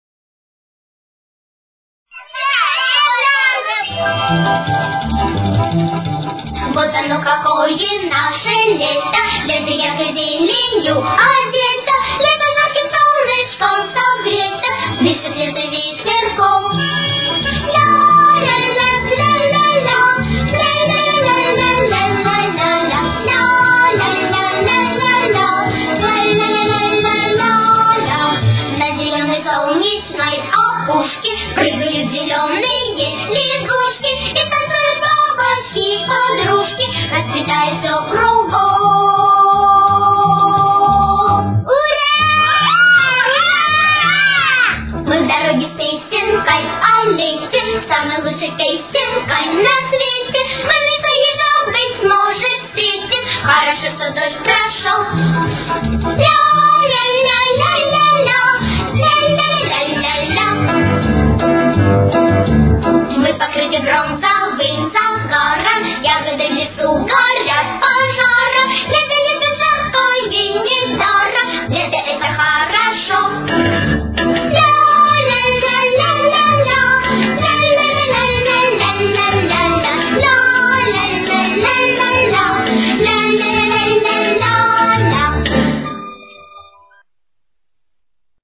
Тональность: Соль мажор. Темп: 89.